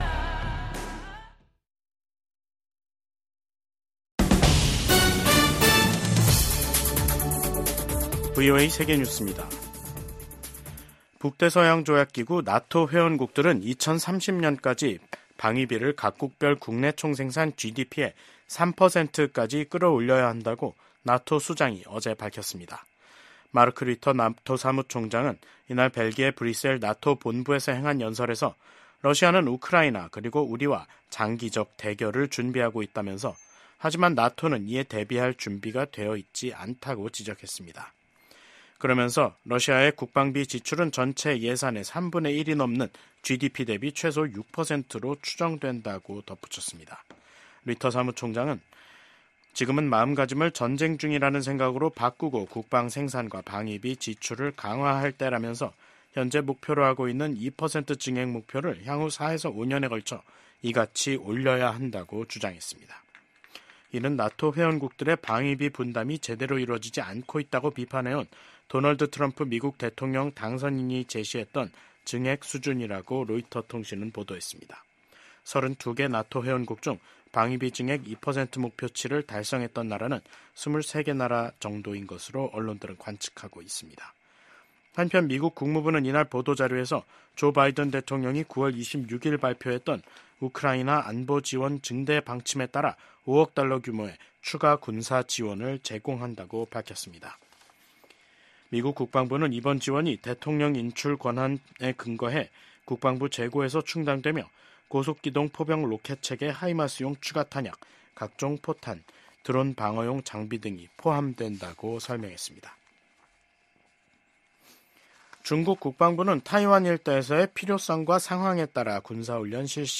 VOA 한국어 간판 뉴스 프로그램 '뉴스 투데이', 2024년 12월 13일 3부 방송입니다. 비상계엄 사태를 일으킨 윤석열 한국 대통령에 대한 탄핵소추안 2차 투표가 내일 진행됩니다. 미국의 전직 고위 관리들은 북한이 연일 한국 대통령의 비상 계엄 선포와 탄핵 정국을 보도하는 것은 한국 정부를 비난하고 미한 동맹을 약화시키려는 선전선동 목적이 크다고 진단했습니다.